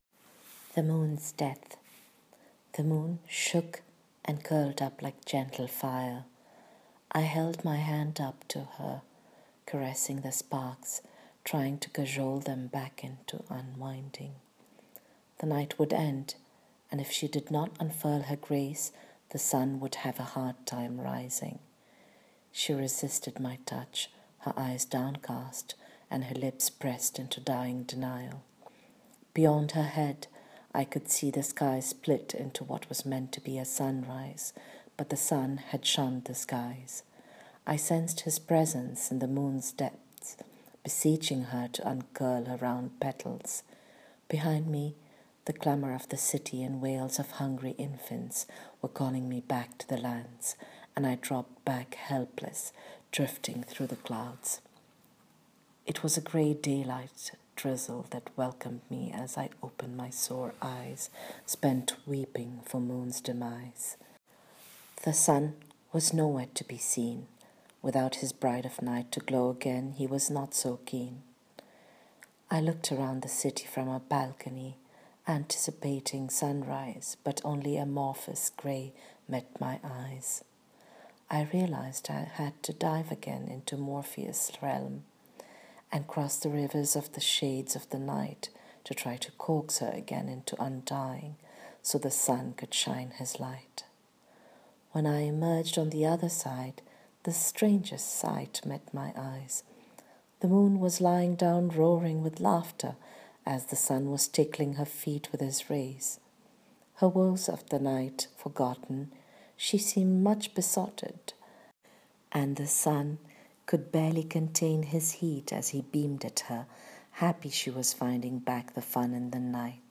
Reading of the flash fiction :